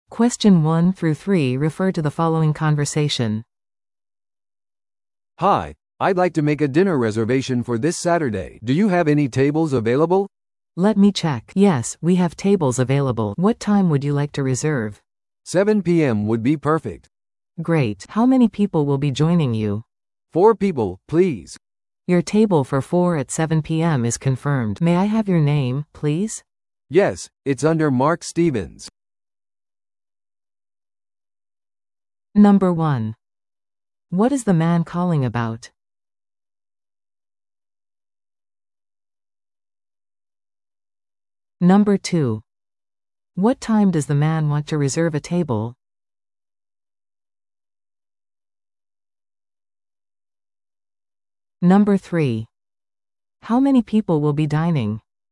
No.1. What is the man calling about?